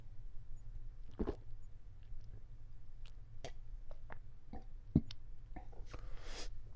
人类的声音 " 啜饮2
用Zoom H1记录。
Tag: 男性 苏打 啜食 饮料 液体